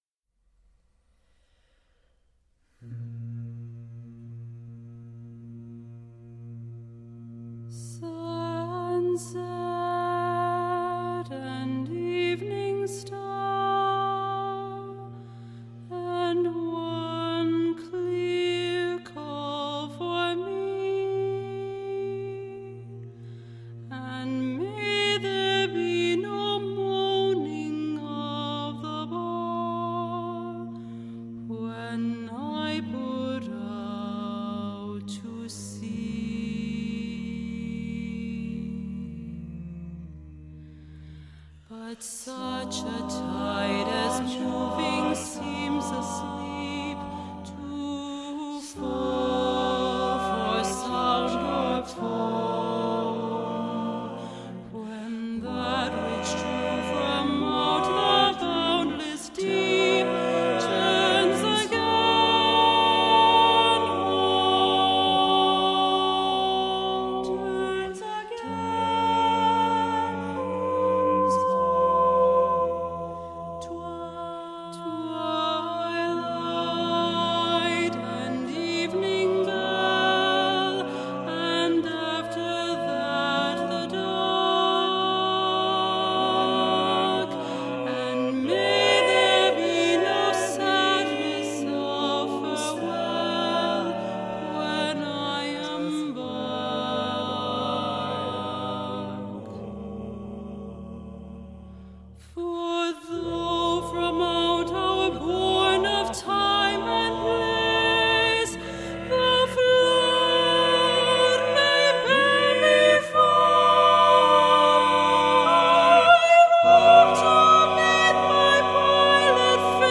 SATB, A solo, a cappella
Simple, warm, blissful.